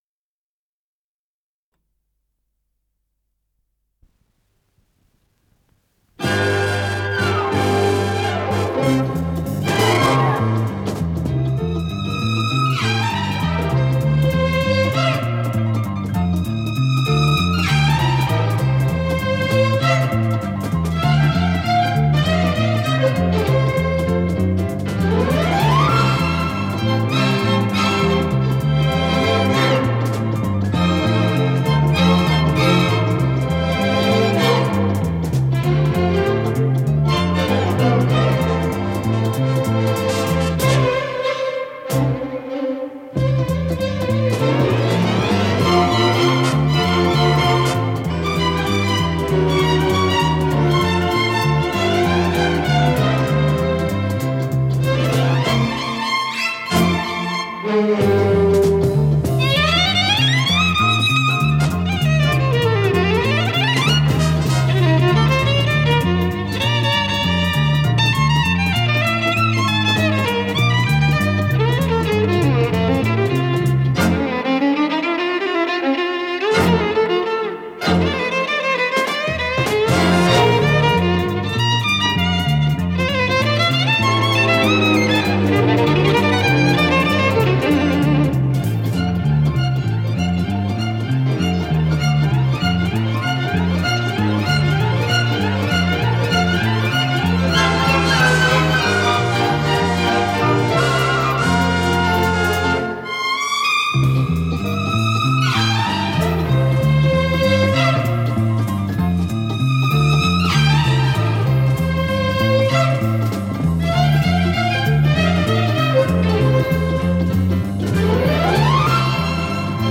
соло на скрипке
ВариантДубль моно